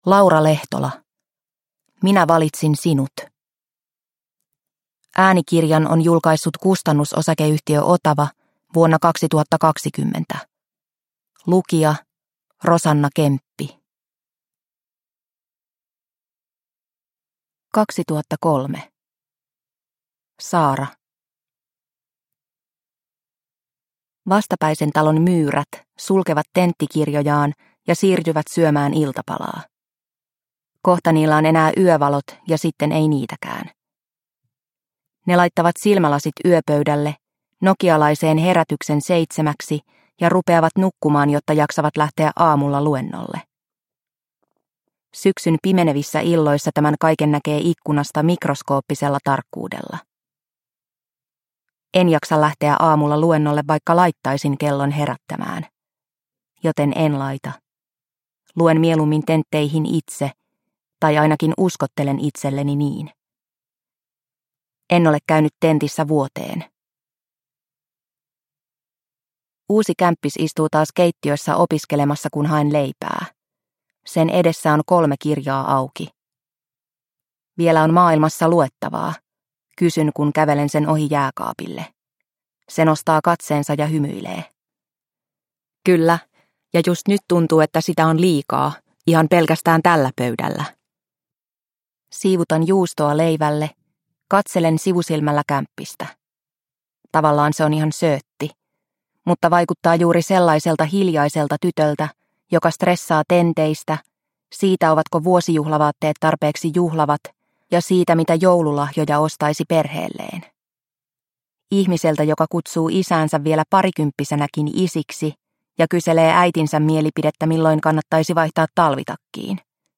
Minä valitsin sinut – Ljudbok – Laddas ner